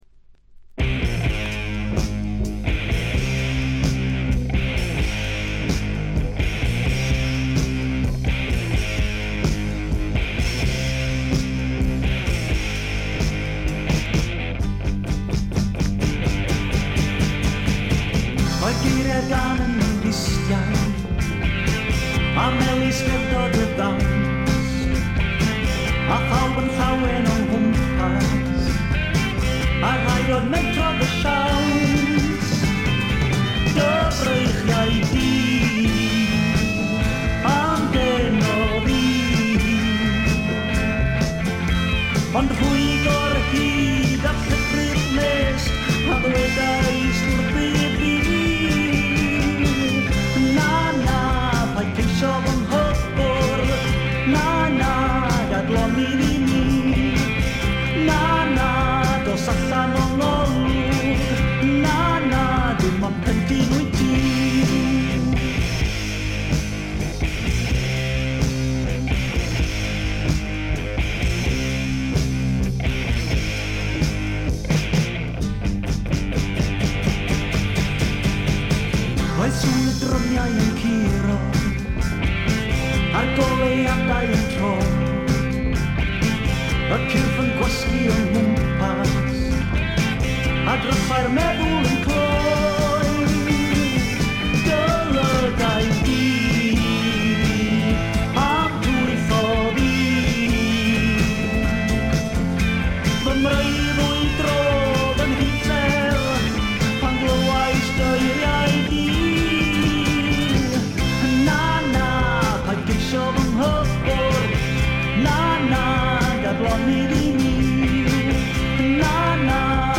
内容はフォーク、アシッド、ポップ、ロックと様々な顔を見せる七変化タイプ。
試聴曲は現品からの取り込み音源です。